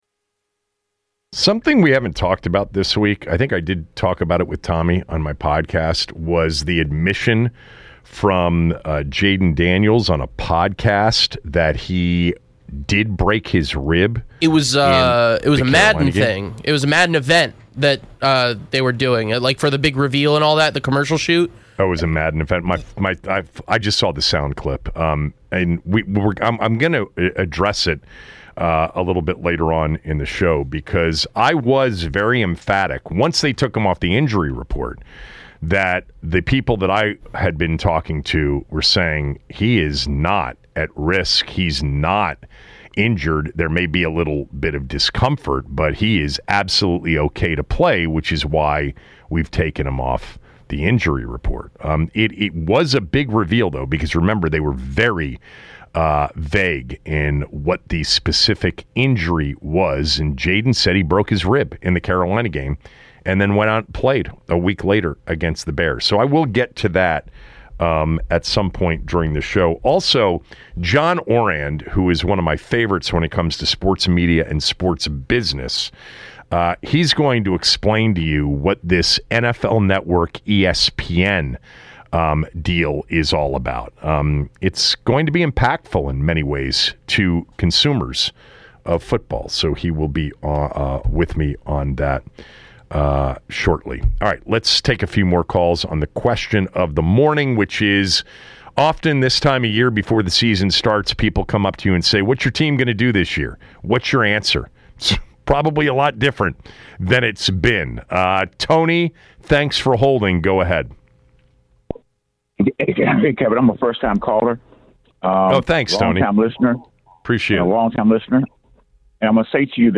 Callers give their predictions for what the Commanders will be in the 2025 season after making the NFC Championship game last season.